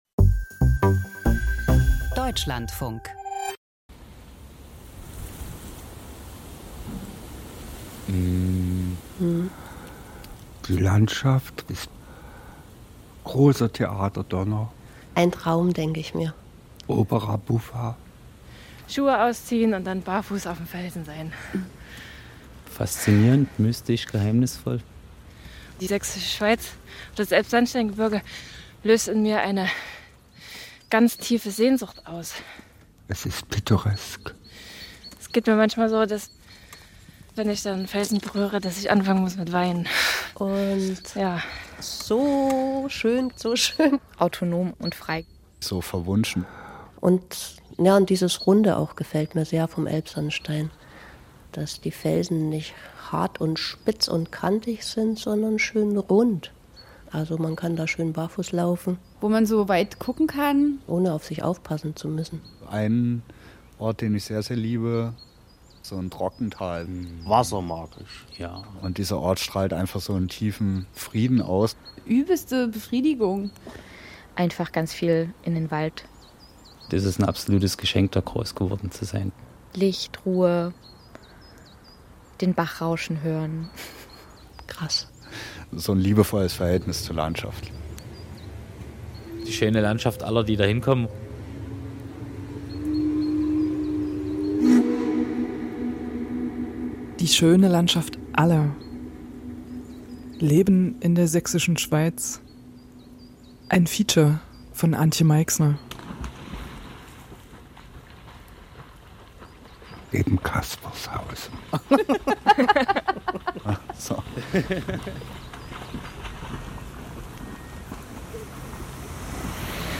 Feature Archiv Leben in der Sächsischen Schweiz Die schöne Landschaft aller 54:34 Minuten Wer in der Sächsischen Schweiz aufgewachsen ist, trägt sie ein Leben lang in sich.